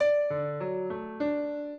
piano
minuet15-4.wav